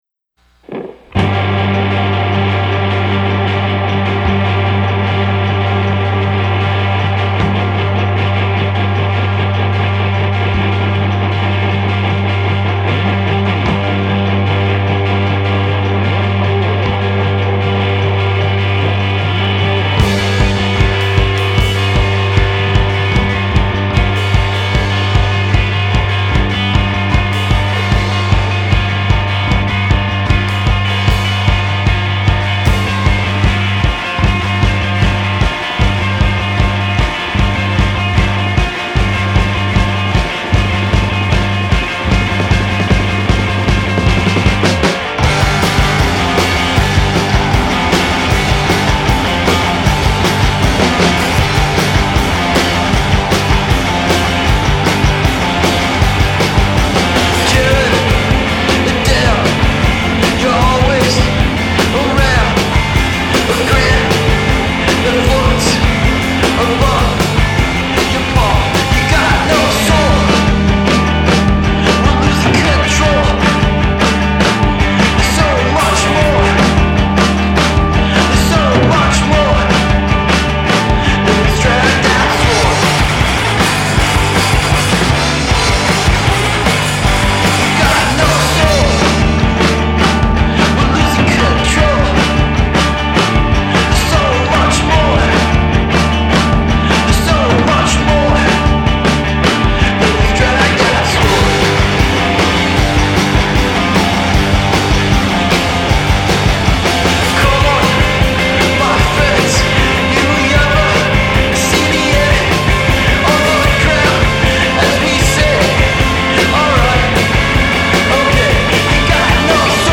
noisy guitars, put to good purpose
Amidst the punk-ish ambiance I sense a great deal of poise.